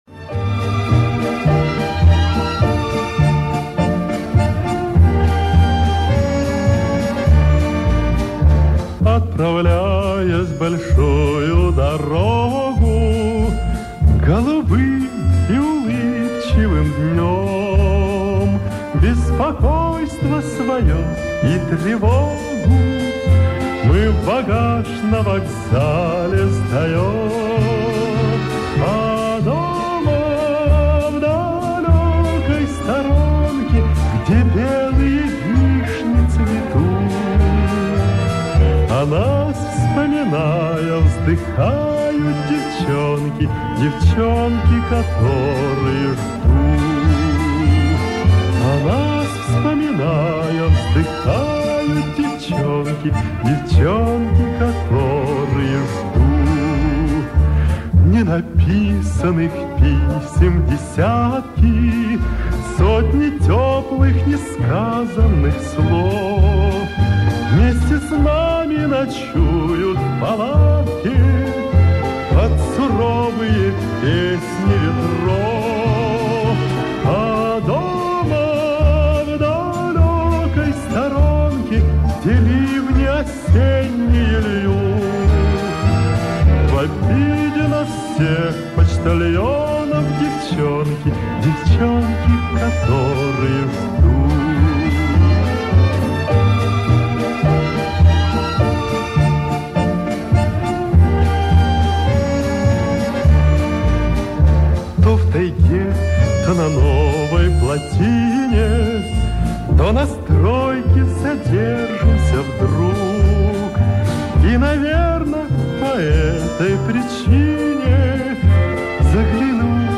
Вот оригинал без ревера.